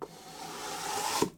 card_drag.ogg